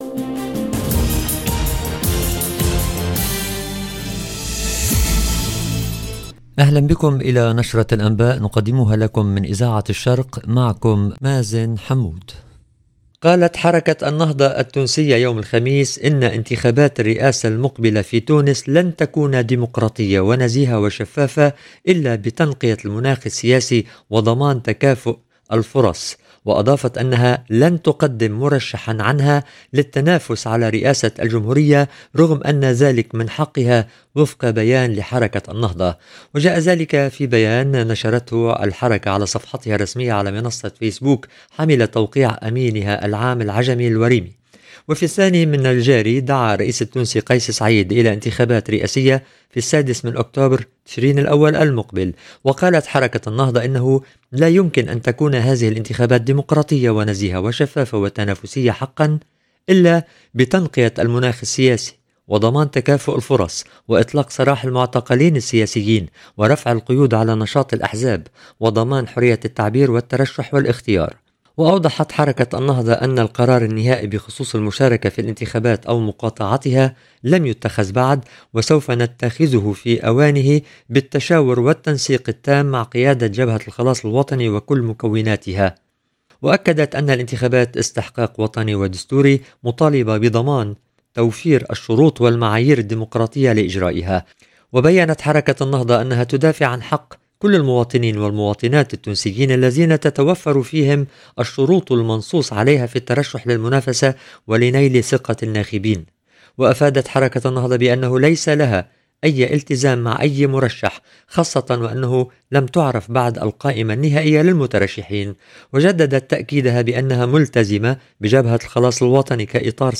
LE JOURNAL EN LANGUE ARABE DU SOIR DU 11/07/24